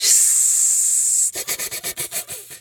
snake_2_hiss_01.wav